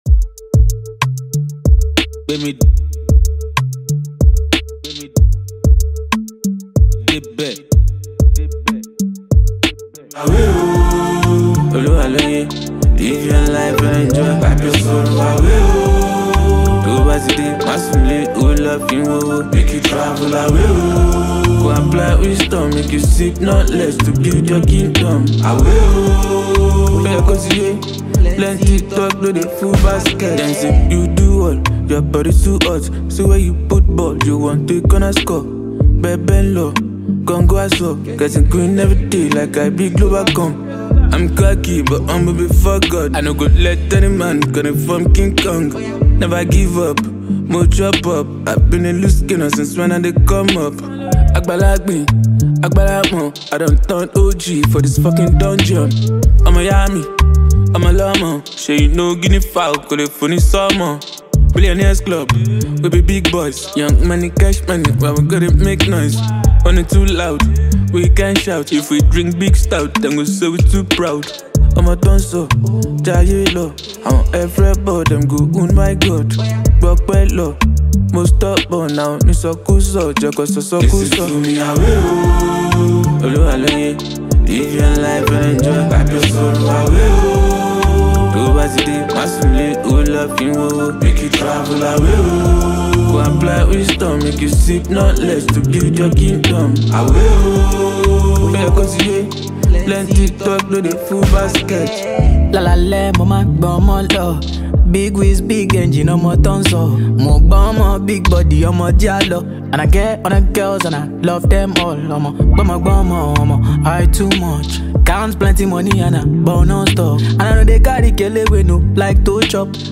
single
Afrobeat
UK-based rapper
With its infectious rhythm and star-studded lineup